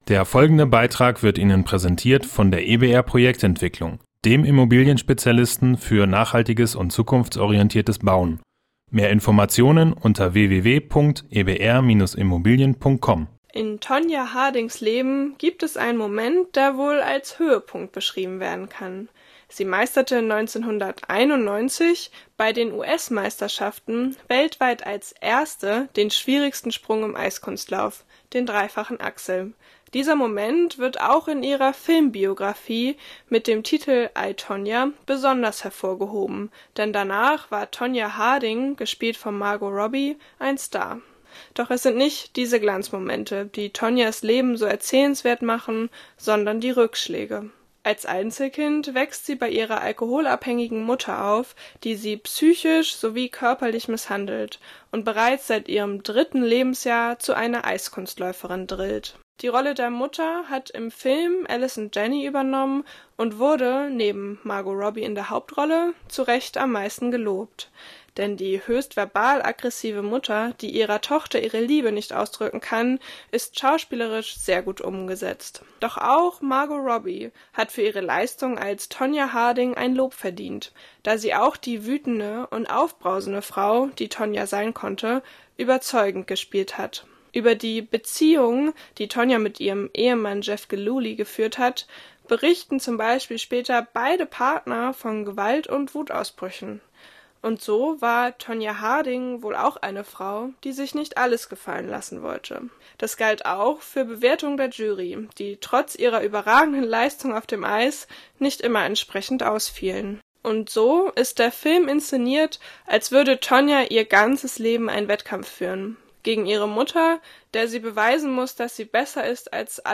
Beiträge > Ein Leben zwischen Tüll und Tyrannei – Filmrezension „I, Tonya“ - StadtRadio Göttingen